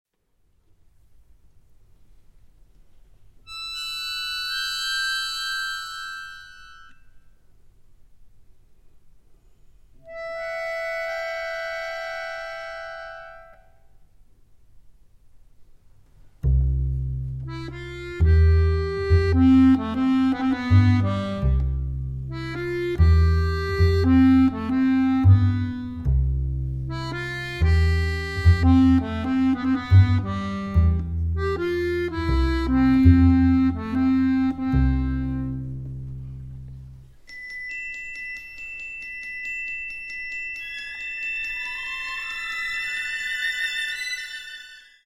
Very spacious (7:30)